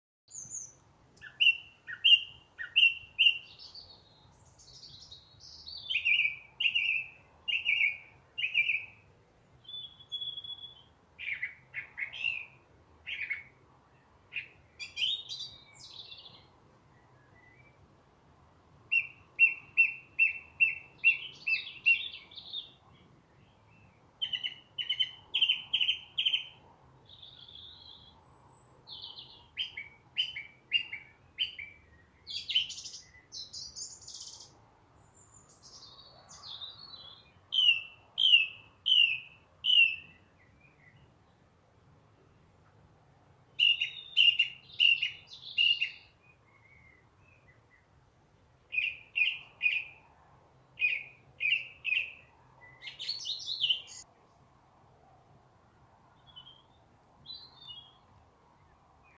Download Song Thrush sound effect for free.
Song Thrush